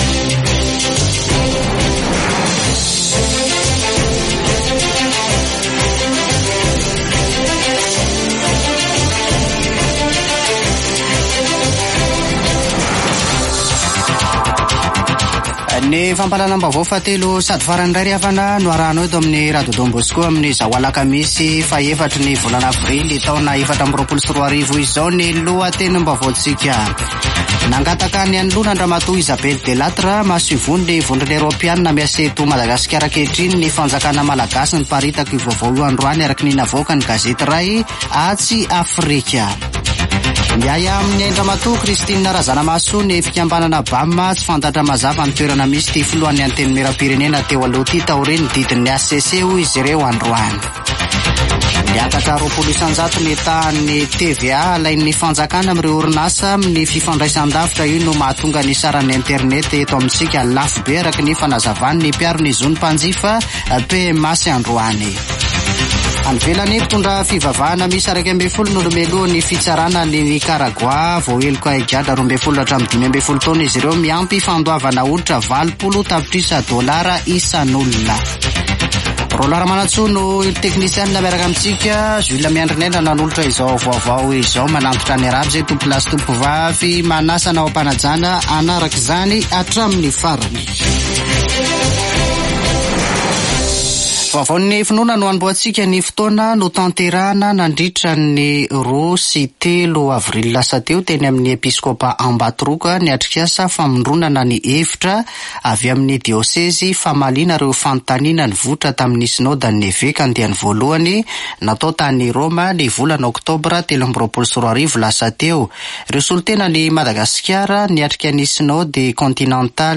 [Vaovao hariva] Alakamisy 4 aprily 2024